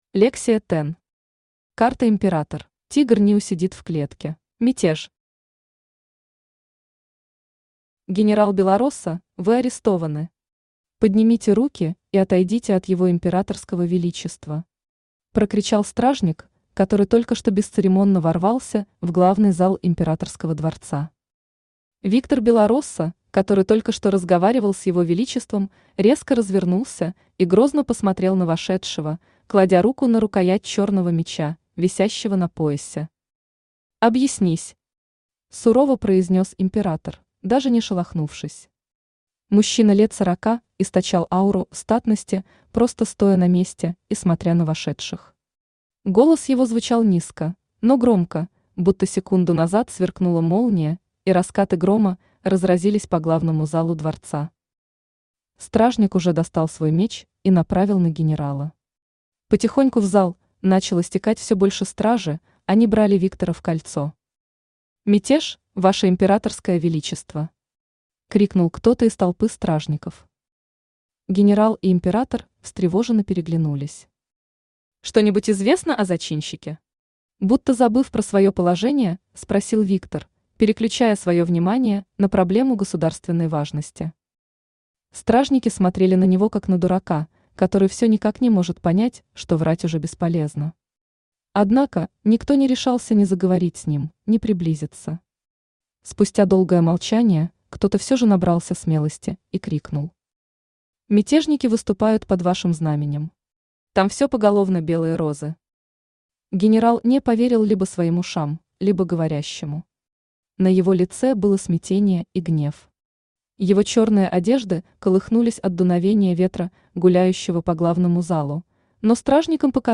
Аудиокнига Карта «Император» | Библиотека аудиокниг
Aудиокнига Карта «Император» Автор Лексия Тен Читает аудиокнигу Авточтец ЛитРес.